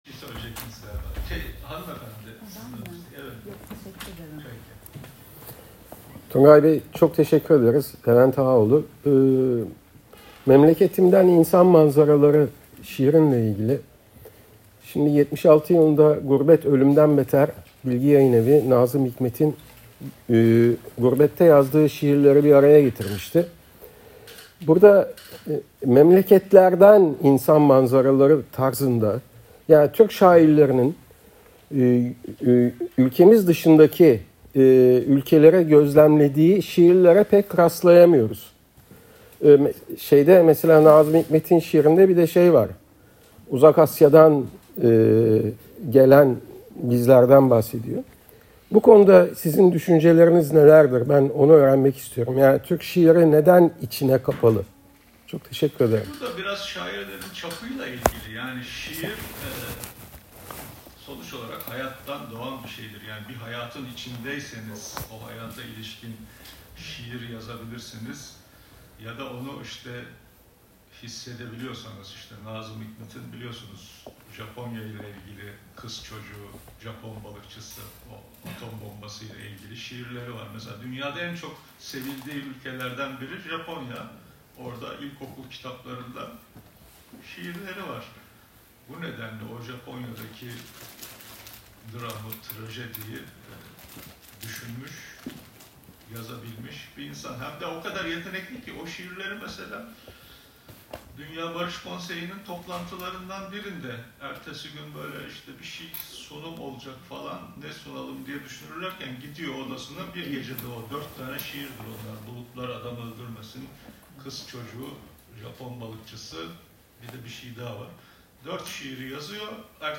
Söyleşi Ses Kaydı